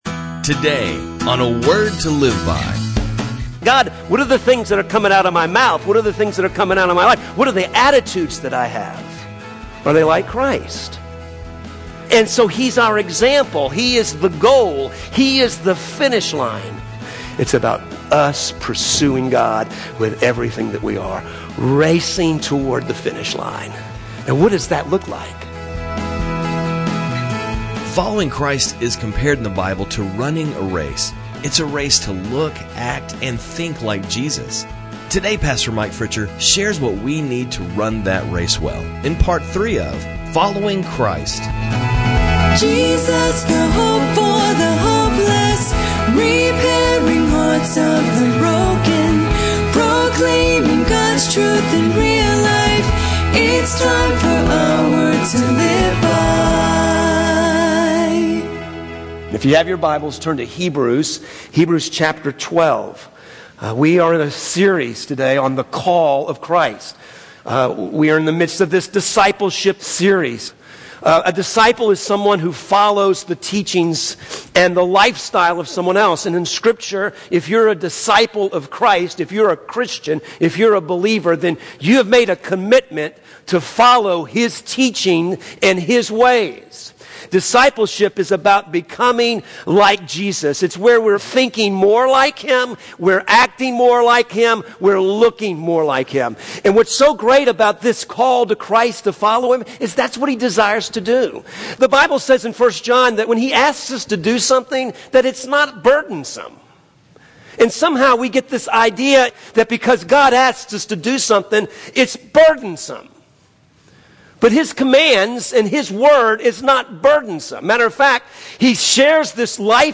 sermons on cd